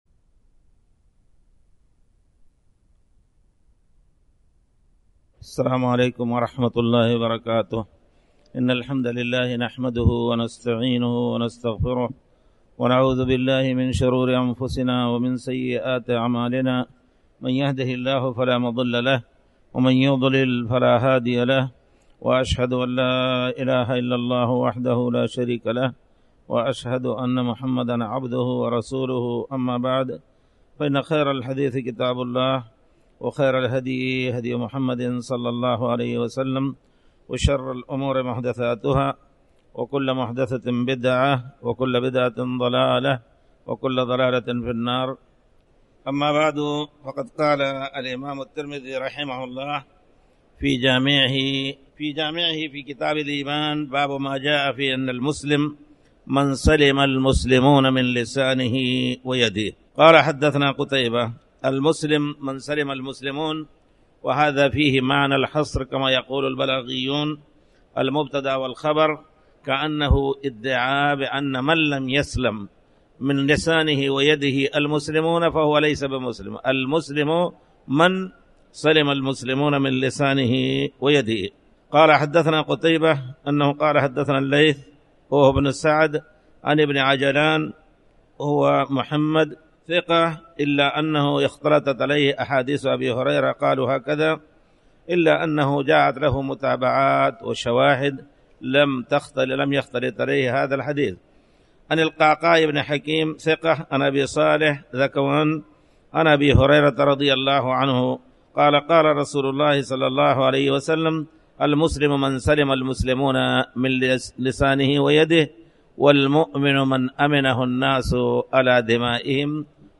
تاريخ النشر ١٨ رجب ١٤٣٩ هـ المكان: المسجد الحرام الشيخ